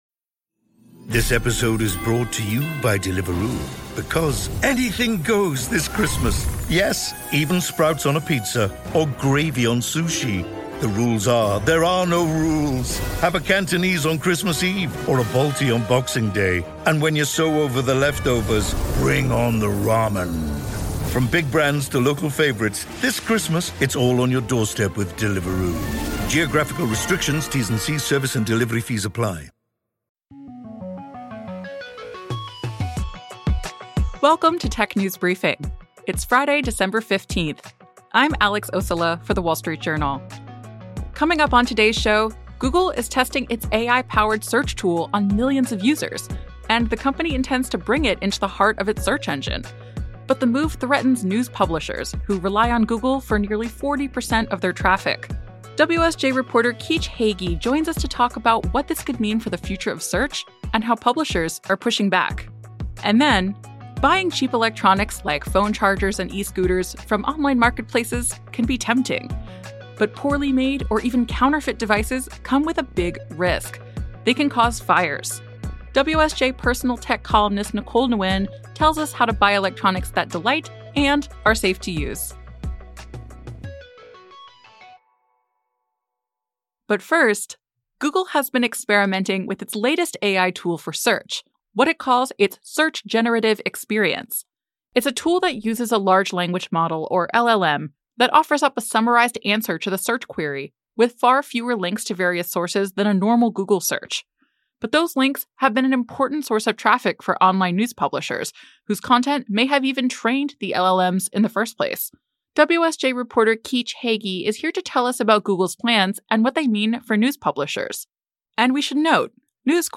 talks with host